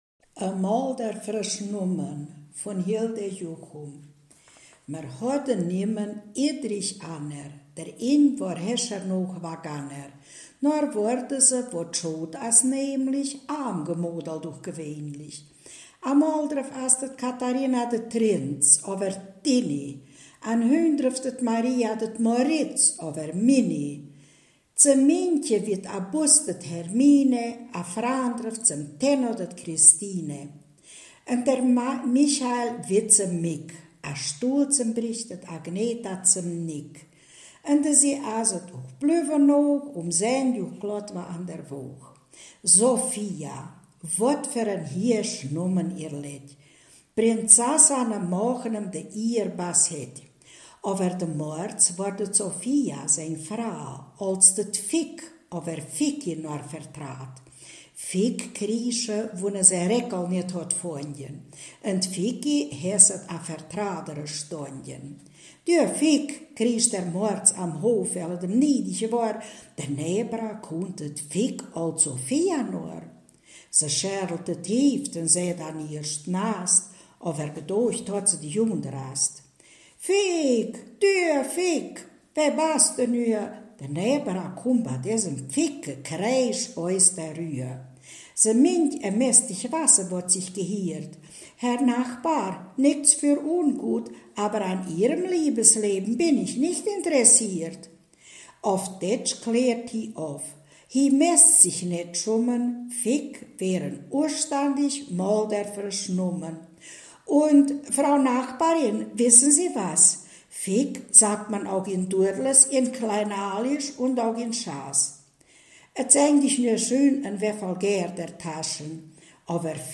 Ortsmundart: Maldorf